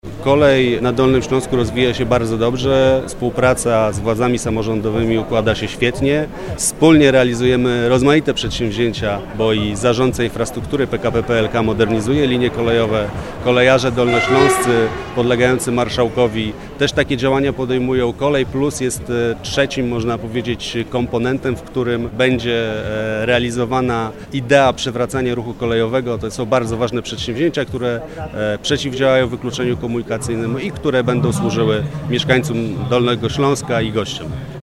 W Legnicy przy ul. Pątnowskiej uroczyście otwarto nową halę serwisową KD.
W otwarciu hali KD uczestniczył także minister Andrzej Bittel, Sekretarz stanu, pełnomocnik rządu ds. przeciwdziałania wykluczeniu komunikacyjnemu.